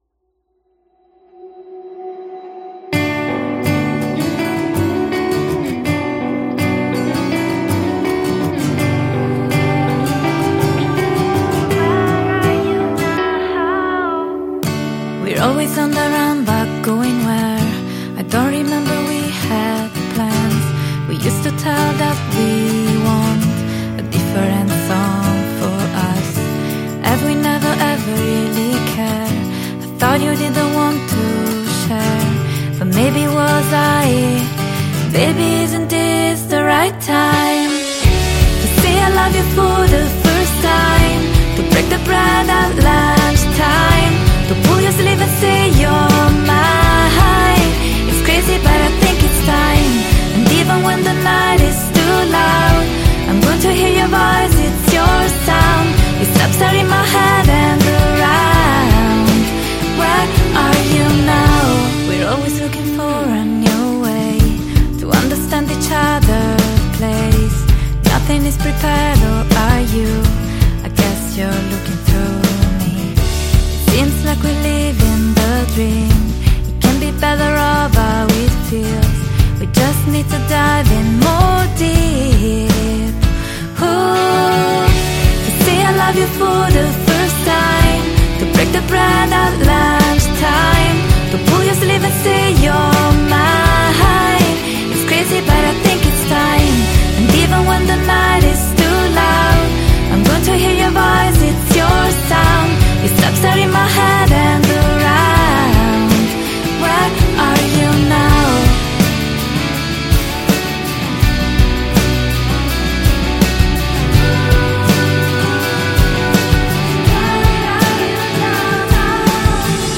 Vocals & Guitar
Recorded and Mixed in Rome & LA.